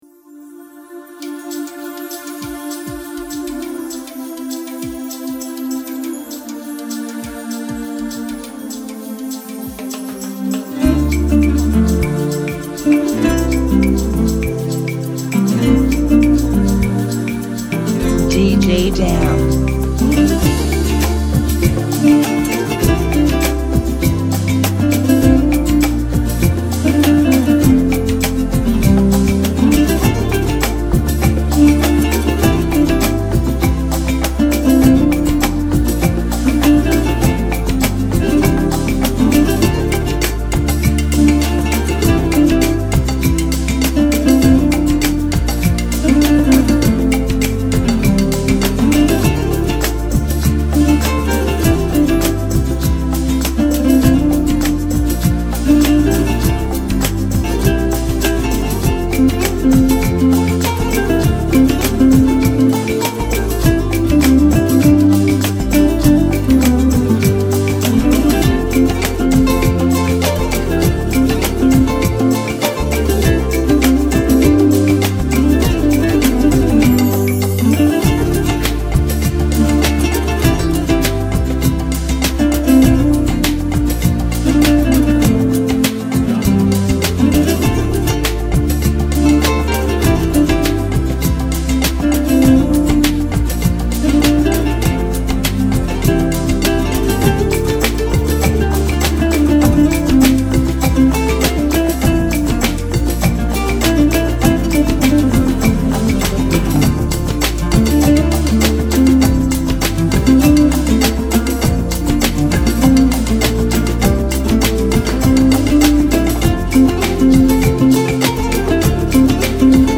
100 BPM
Genre: Salsa Remix